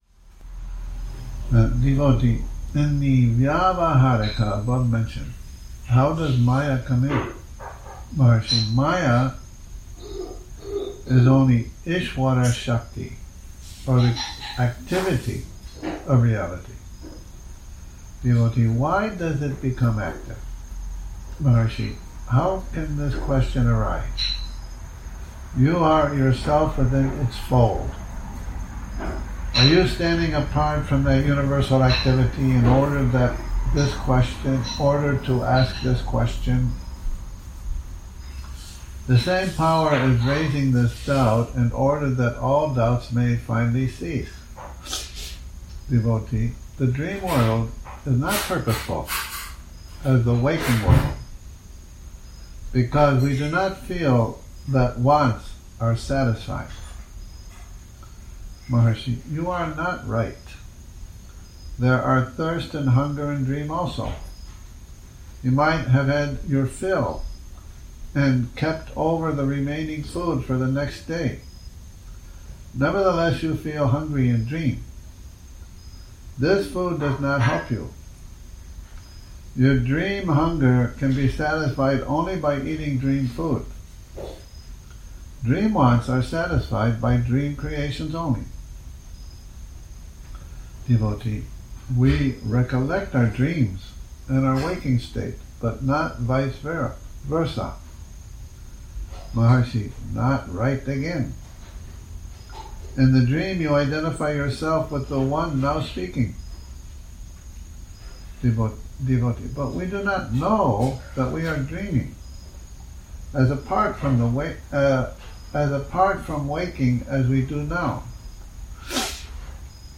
Morning Reading, 02 Oct 2019
a reading from 'Talks', #399 - #416 Morning Reading, 02 Oct 2019 recording begins near the end of the 4th page after the beginning of talk #399